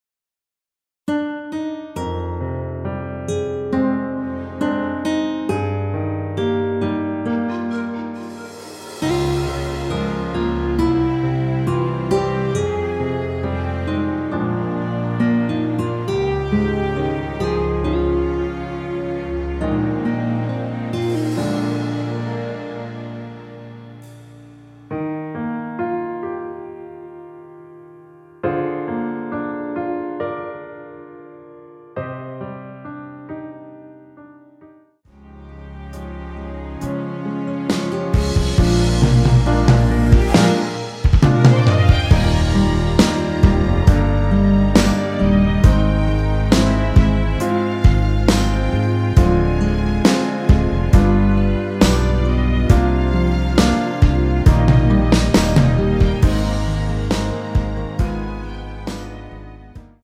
원키에서(-2)내린MR입니다.
Eb
앞부분30초, 뒷부분30초씩 편집해서 올려 드리고 있습니다.
중간에 음이 끈어지고 다시 나오는 이유는